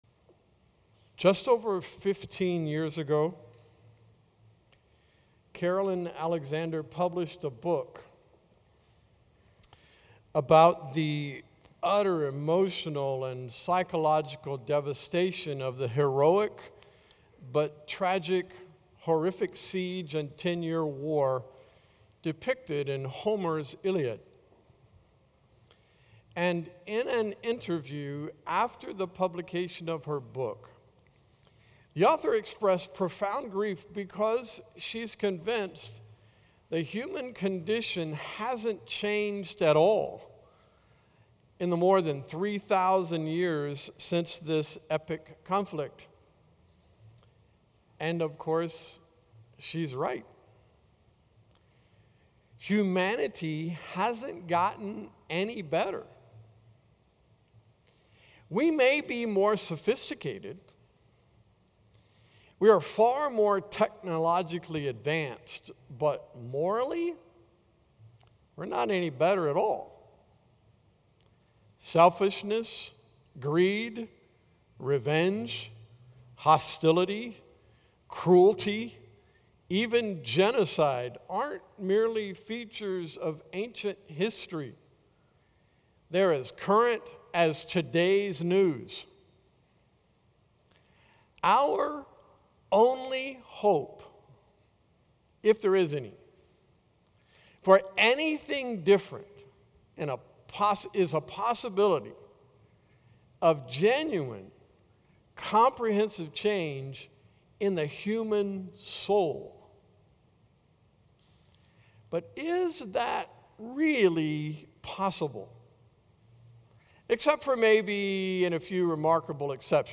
Message: “Unashamed to Change